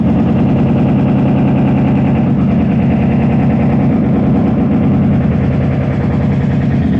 建筑工地镐头
描述：听到施工现场的声音。
标签： 钻孔 工人 拆卸 破碎机 jack-锤 钻头 千斤顶 建筑 工具 机械 站点 建设者 锤子 施工现场 混凝土 挖掘机 建设 气动 拆除 建设
声道立体声